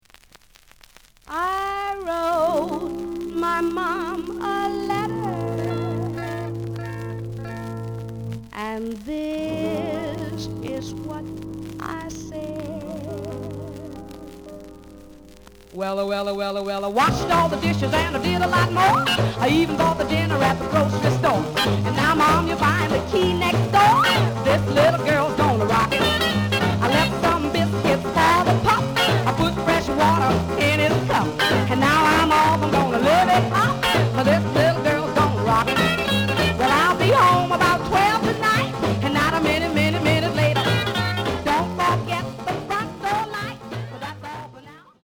The audio sample is recorded from the actual item.
●Format: 7 inch
●Genre: Rhythm And Blues / Rock 'n' Roll
Some noise on A side.